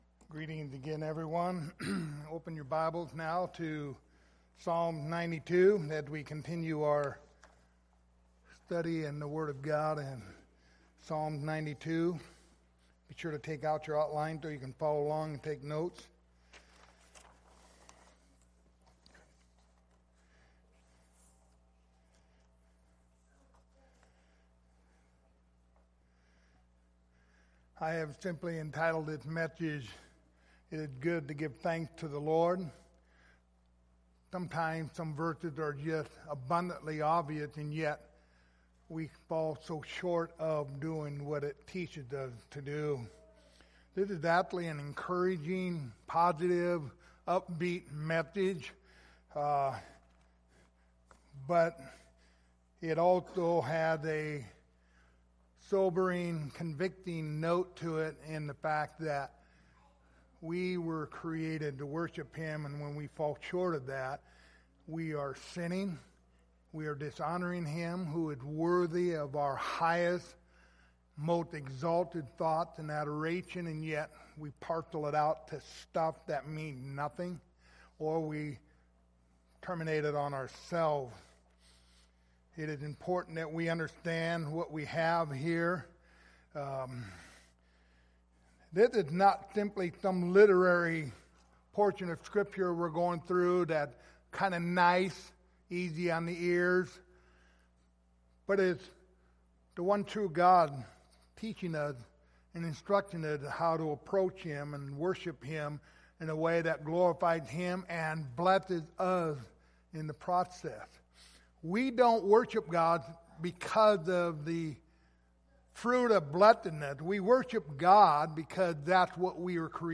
Passage: Psalms 92:1-15 Service Type: Sunday Morning Topics